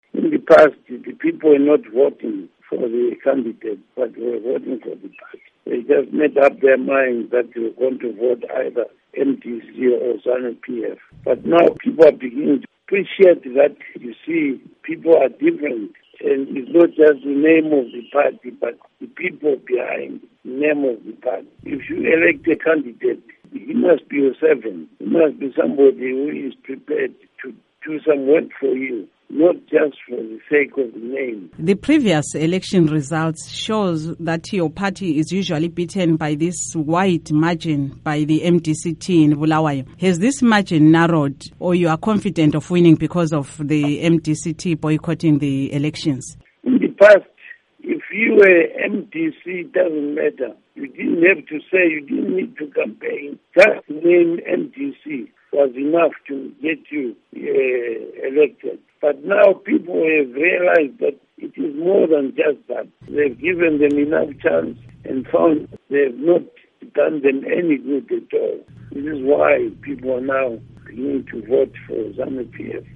Interview with Tshinga Dube